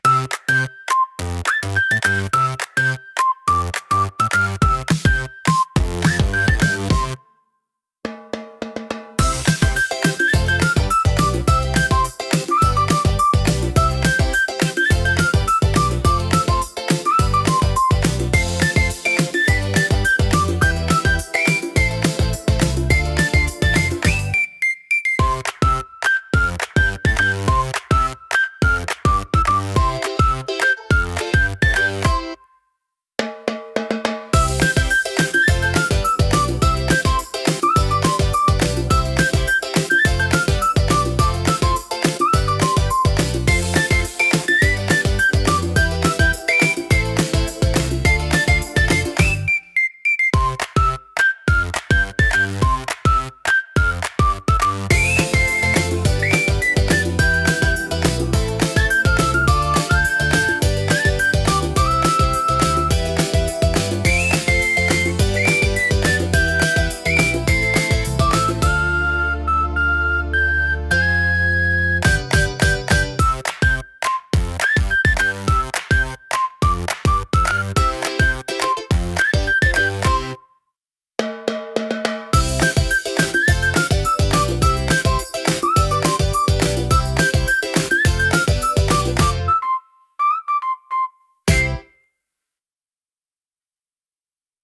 軽快なリズムと遊び心あふれるメロディが、聴く人に楽しさと自由なエネルギーを届けます。
気軽で楽しい空気を作り出し、場を明るく盛り上げるジャンルです。